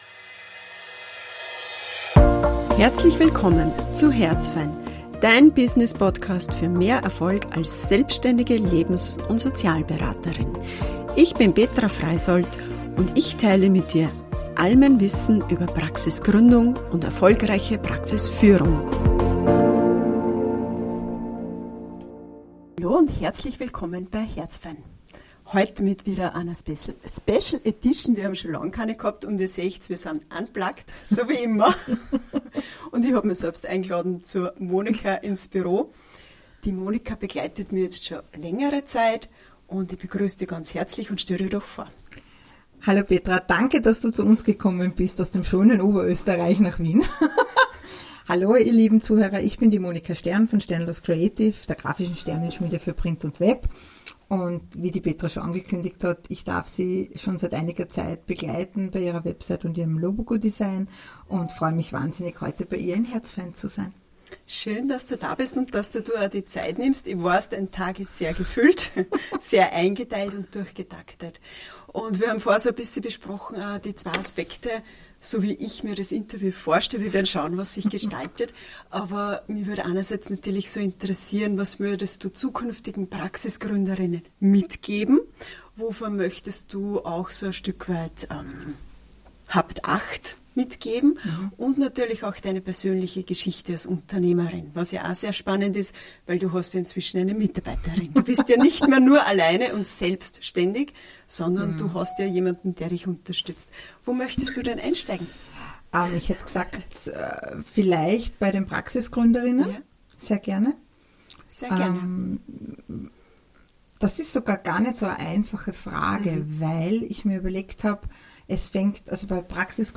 Ein tiefgehendes Interview rund um Webseiten, Corporate Identity und mehr.